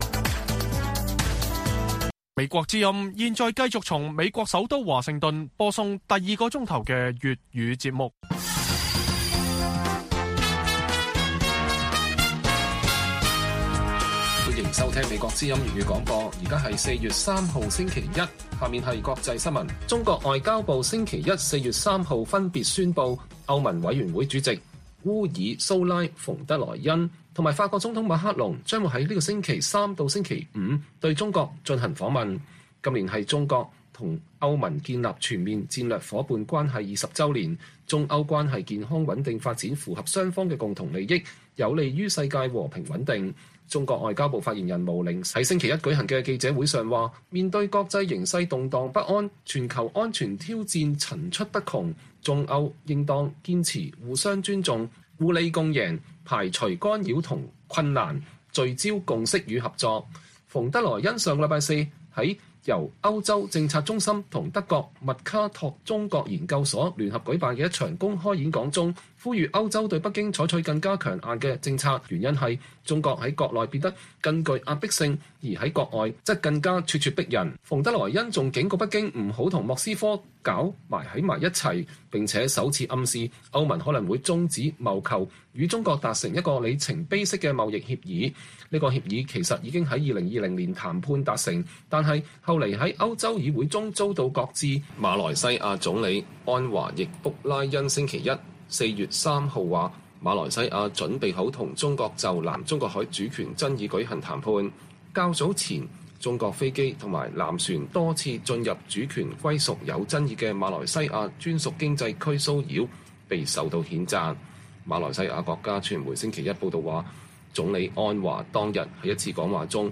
粵語新聞 晚上10-11點: 歐盟主席將與法國總統聯袂訪華